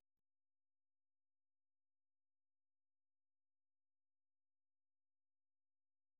Форма симфоническая поэма
Произведение написано в тональности ре минор и является примером ранних тональных работ Шёнберга.